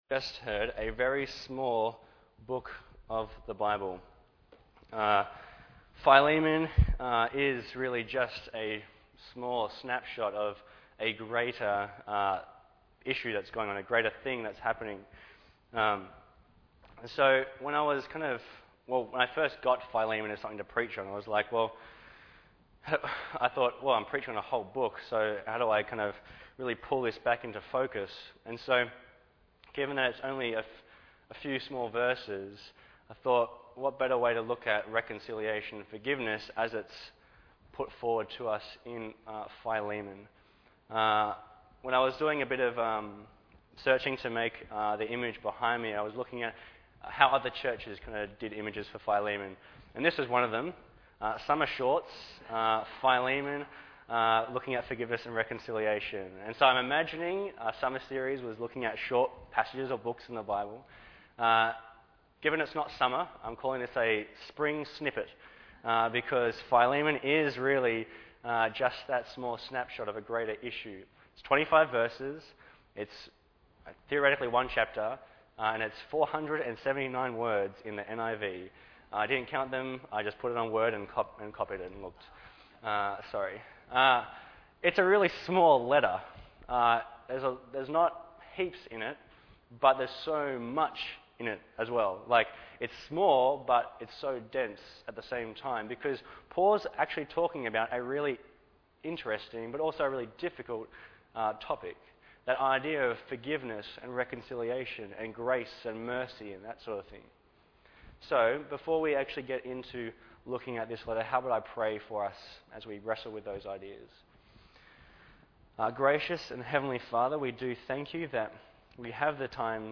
Bible Text: Philemon | Preacher